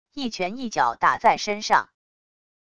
一拳一脚打在身上wav音频